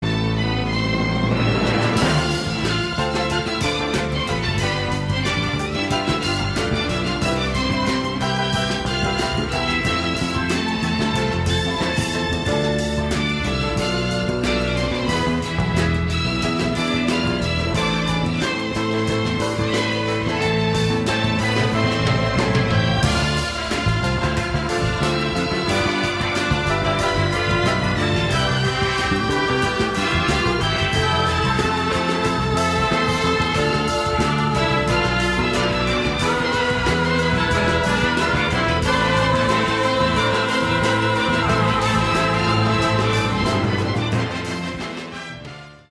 Symphonie